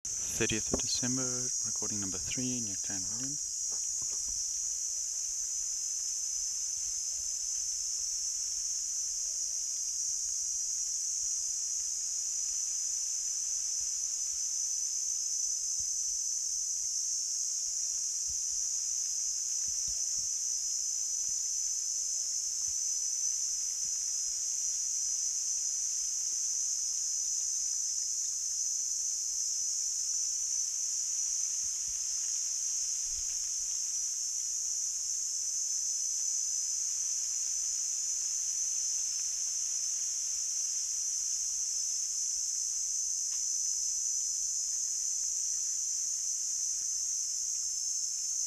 Platypleura "sp. 11 cf. hirtipennis"